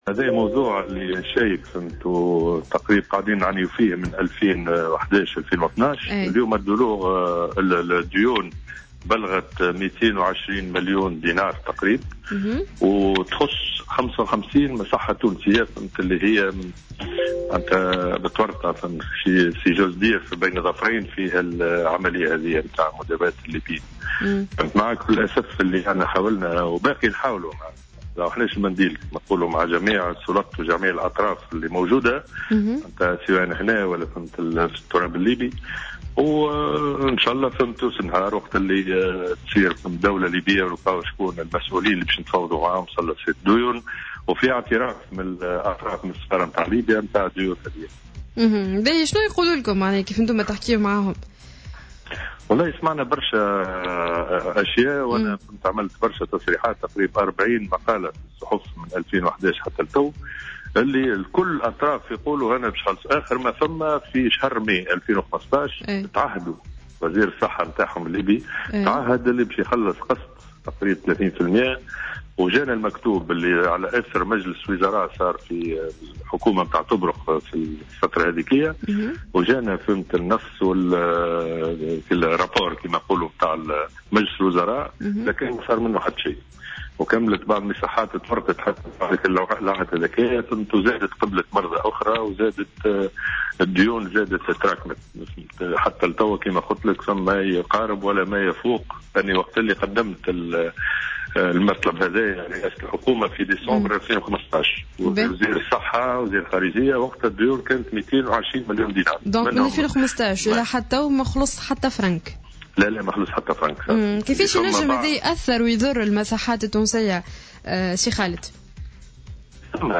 وأضاف في تصريح ل"الجوهرة أف أم" أن الأمر زاد تعقيدا بسبب تراكم ديون المرضى الليبيين لفائدة 55 مصحة منذ سنة 2011 والتي بلغت 220 مليون دينار، وفق تعبيره.